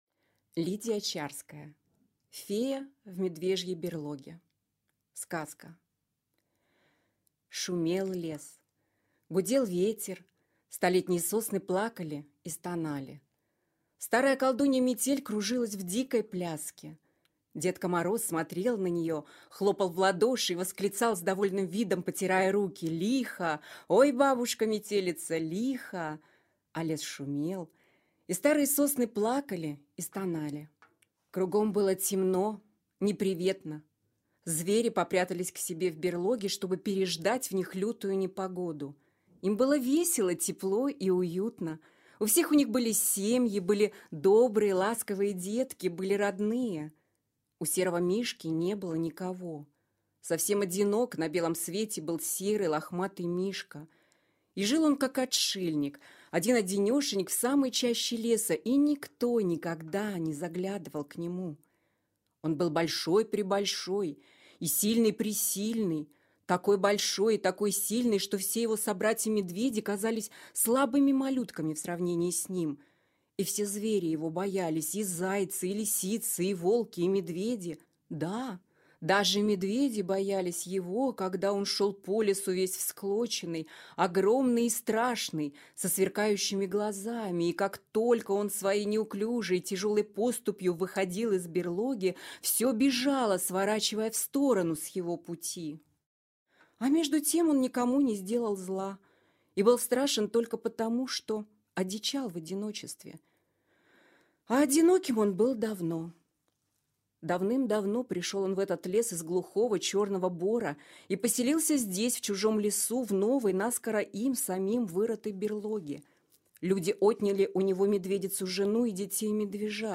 Аудиокнига Фея в медвежьей берлоге | Библиотека аудиокниг